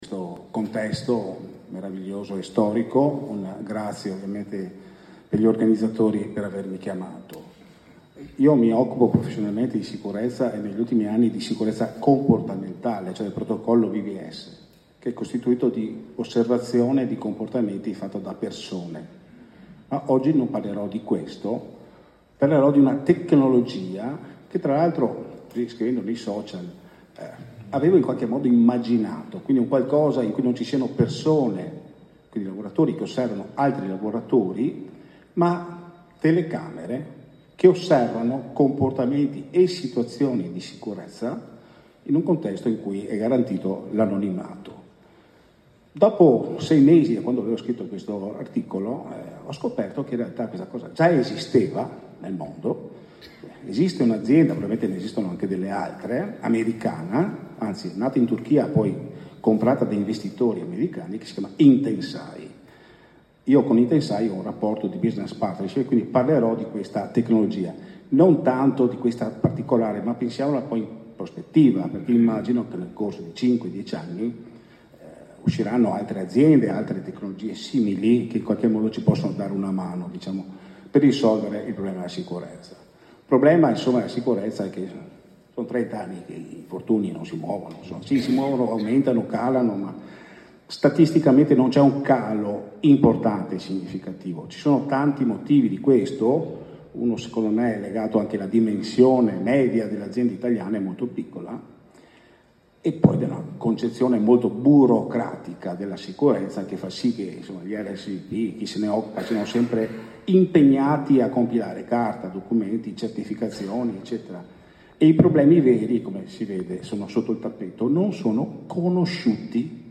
L’intervento al convegno internazionale “Impact of New Technologies on Work’s Health and Safety” organizzato dall’Università di Padova